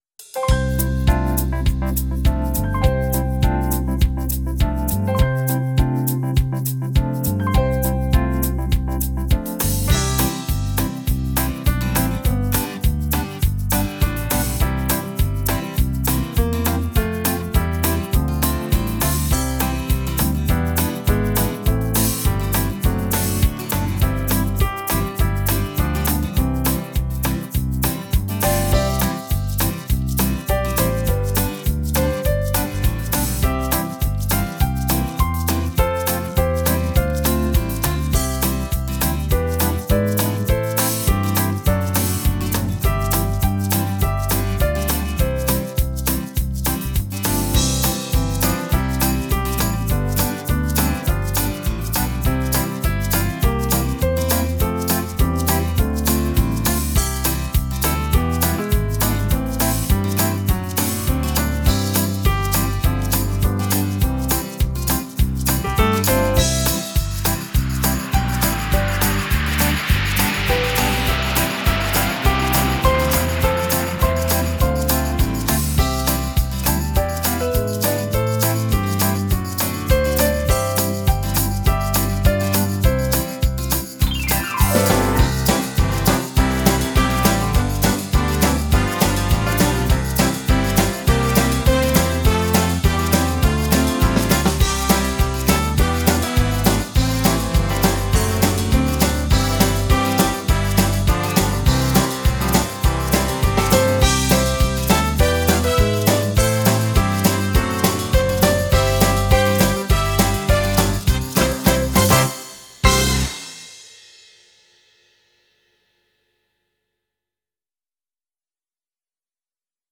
(just instruments) or the choir, please click on the